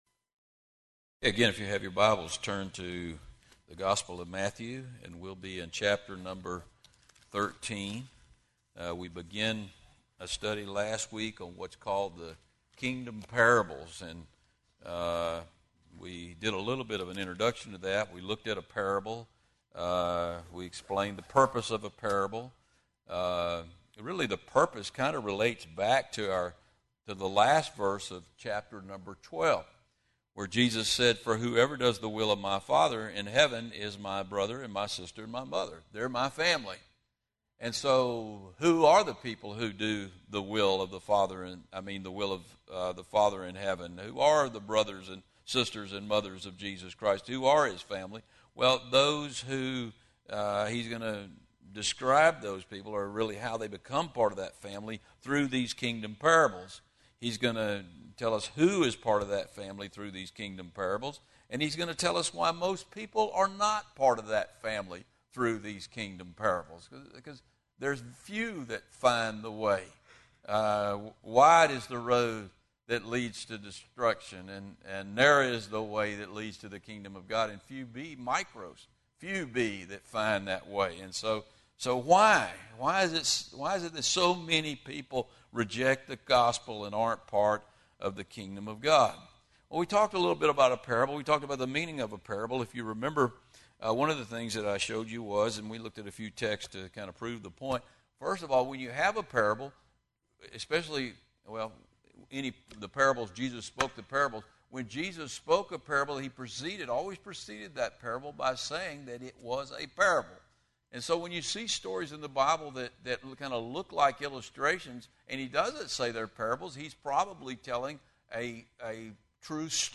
From Wednesday evening service.